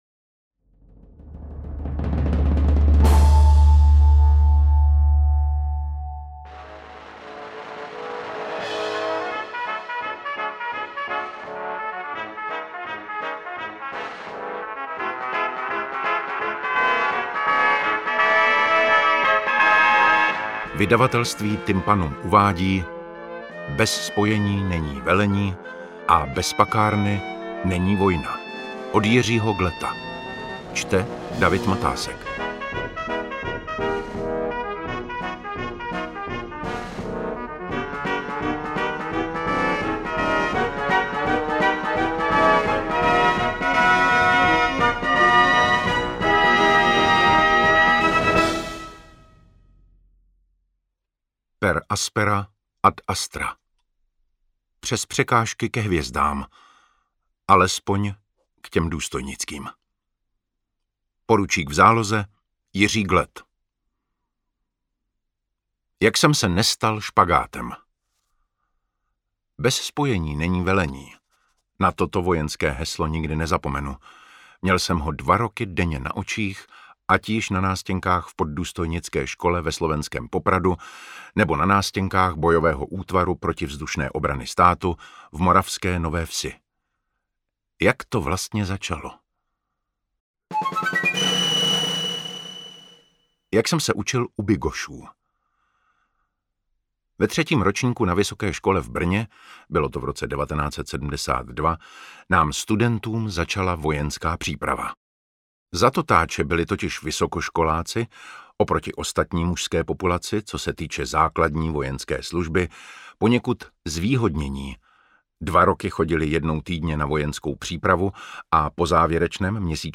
Interpret:  David Matásek
AudioKniha ke stažení, 9 x mp3, délka 2 hod. 52 min., velikost 393,5 MB, česky